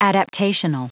Prononciation du mot adaptational en anglais (fichier audio)
adaptational.wav